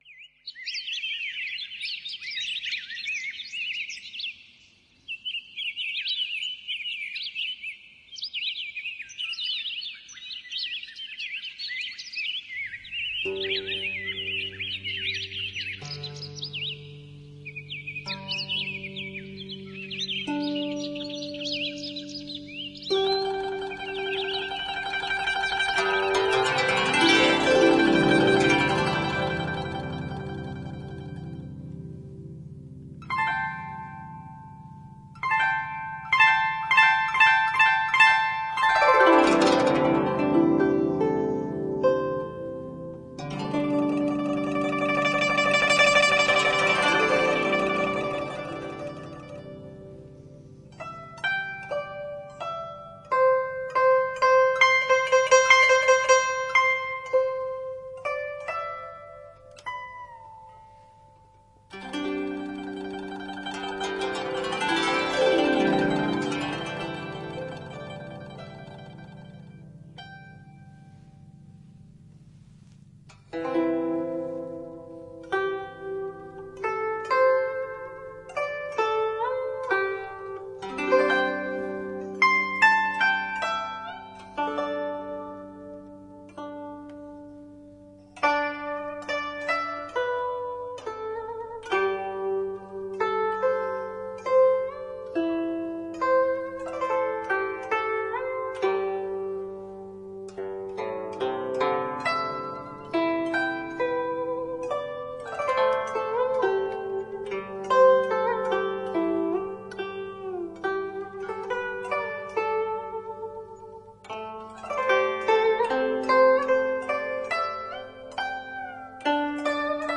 在PC上，表现此曲对声卡和音箱都是一个较大的挑战，若感觉效果不好，还是少听为妙。